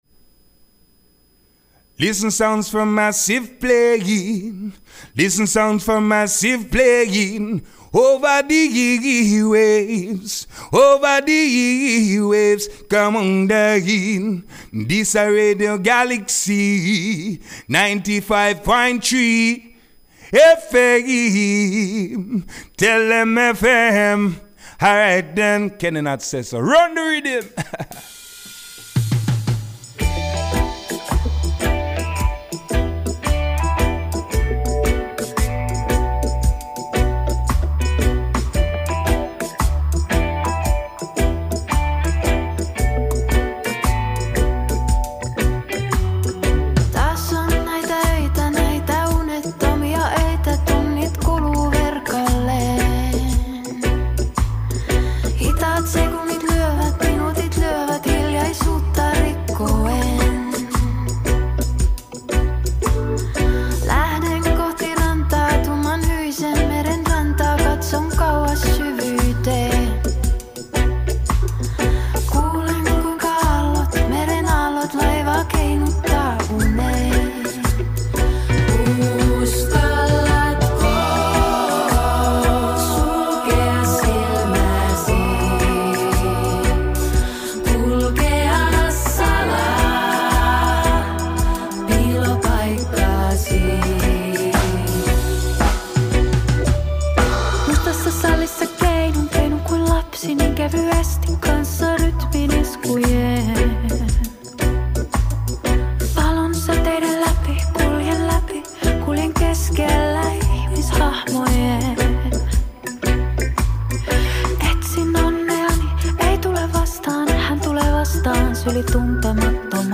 reggaephonique
enregistré lundi 23 juin dans les studios